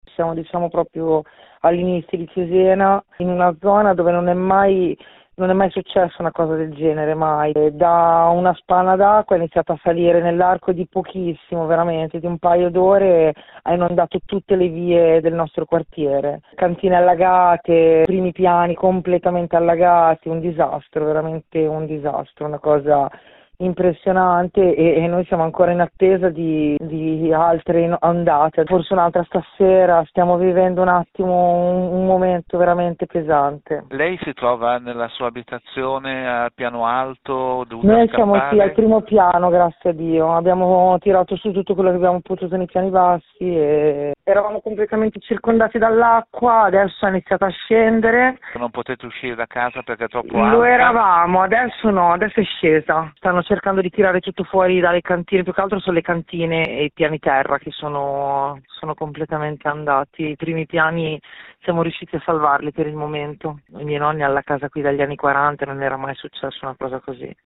Questa la testimonianza di una cittadina che racconta il momento che sta vivendo: